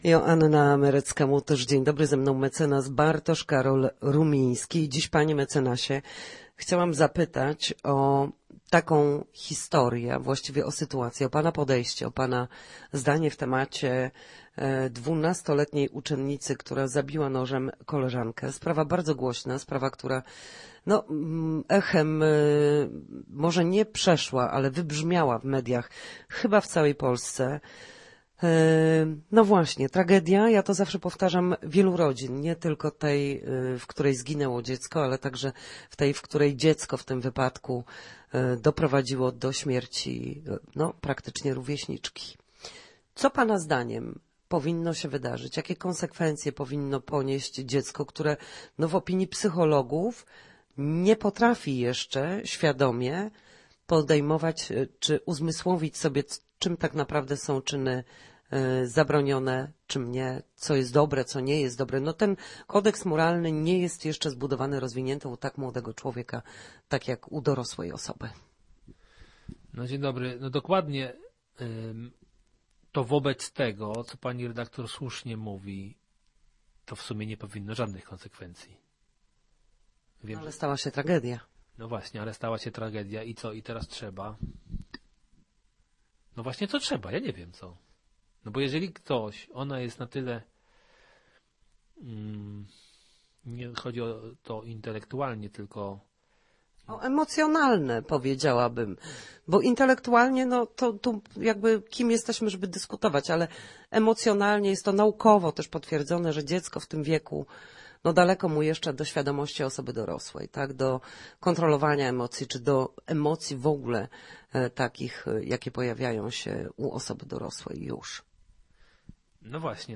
W każdy wtorek o godzinie 13:40 na antenie Studia Słupsk przybliżamy meandry prawa.